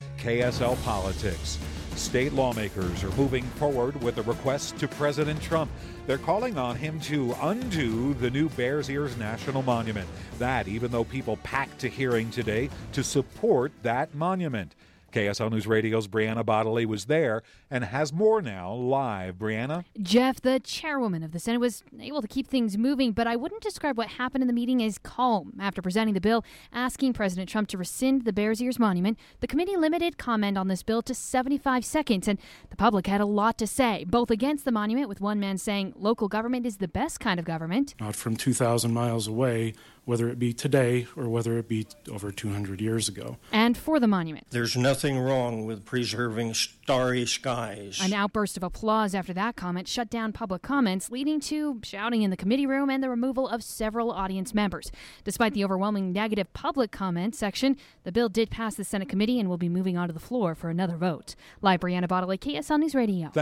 Most of the two rooms they opened up to the audience was filled with people against the resolution. When the chairwoman shut down comments, things got a little heated.